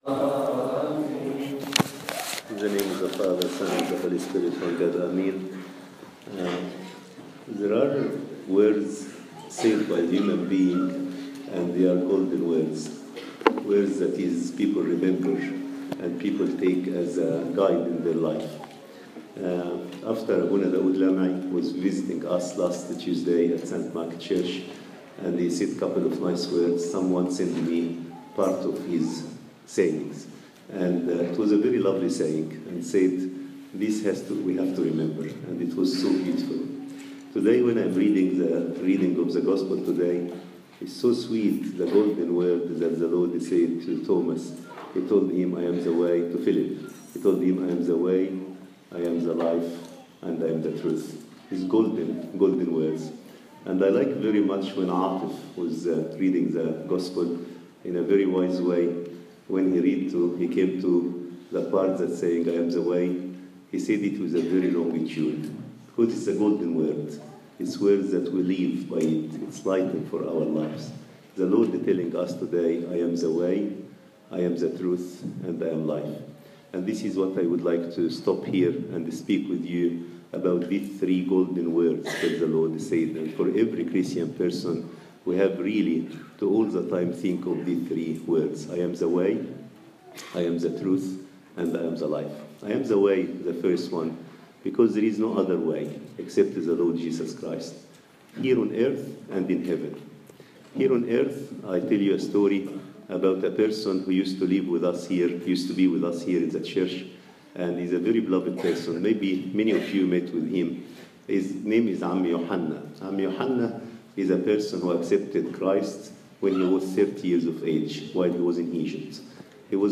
Service Type: English Sunday Sermon